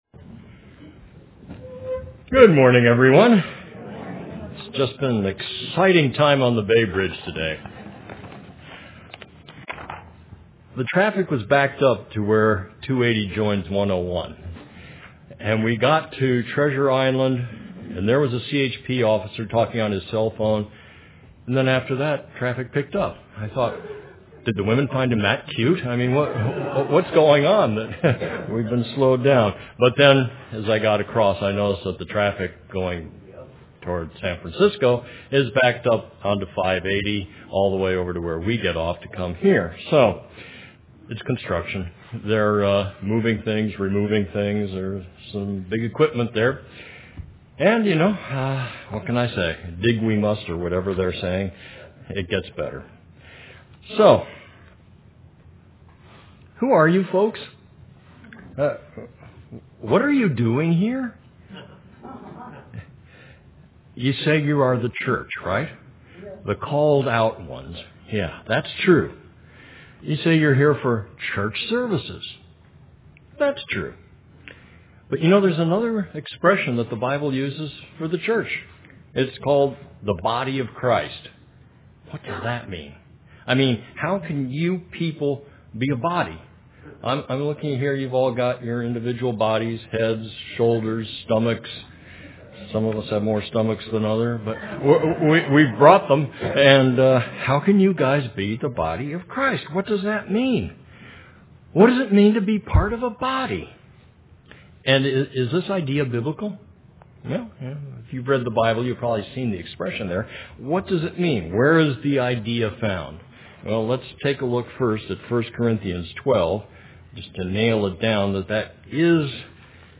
UCG Sermon Notes A Partial list of the Scriptures: Eph 5:30 For we are members of his body, of his flesh, and of his bones.